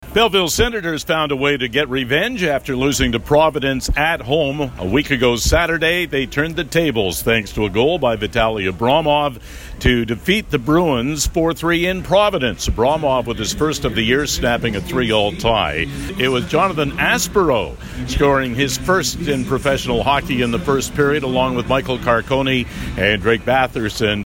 Here is a game report